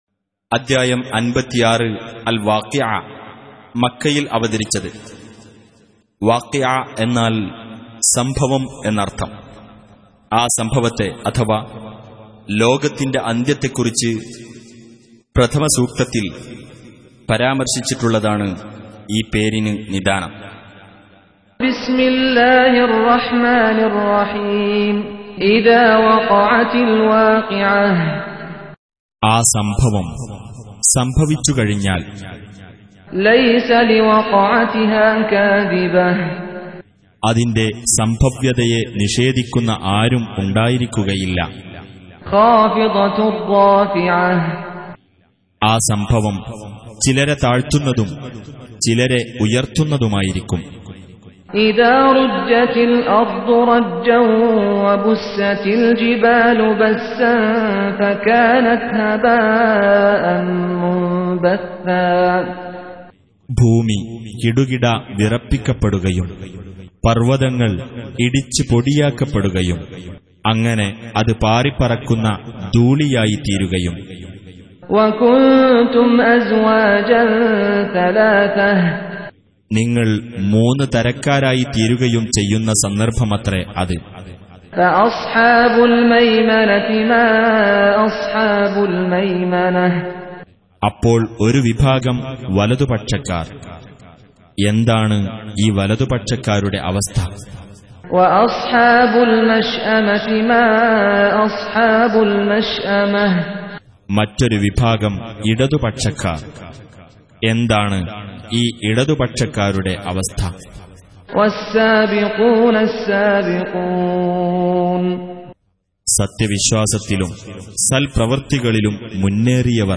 Surah Al-W�qi'ah سورة الواقعة Audio Quran Tarjuman Translation Recitation Tarjumah Transliteration Home Of Malayalam Translation Of The Holy Quran Recitation :: വിശുദ്ധ ഖുർആൻ പാരായണത്തിന്റെ മലയാളം വിവർത്തനം - Audio Quran Listing Reciters, Qur'an Audio, Quran Download,